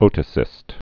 (ōtə-sĭst)